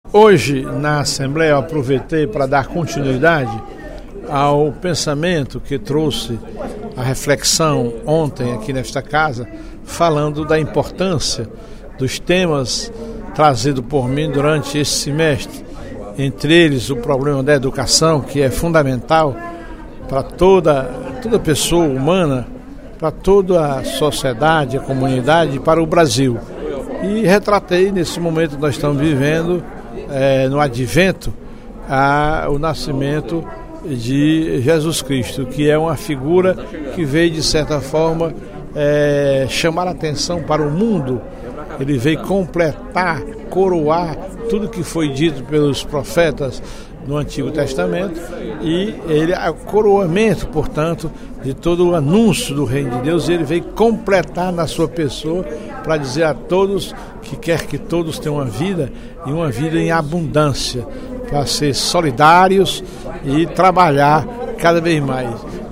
No primeiro expediente da sessão plenária desta quinta-feira (13/12), o deputado Professor Teodoro (PSD) ressaltou a importância da família, da religiosidade e da educação no desenvolvimento da sociedade.